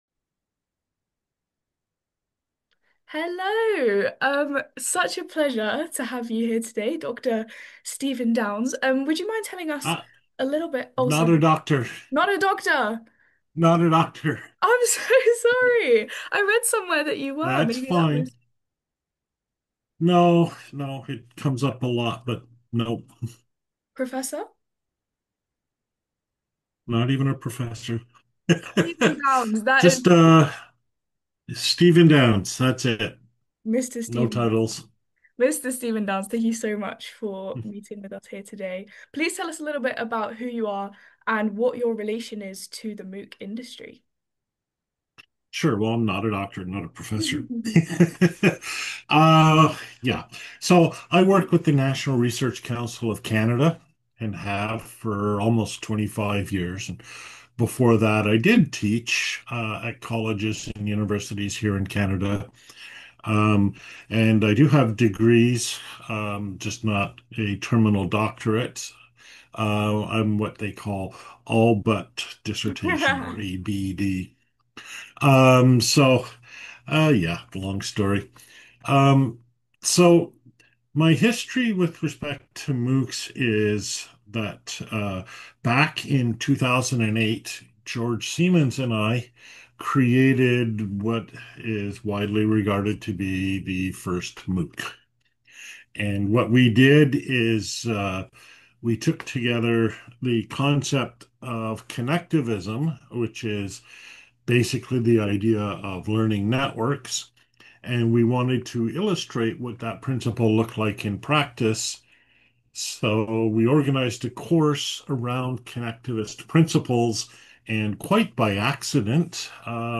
MOOC Institute Interview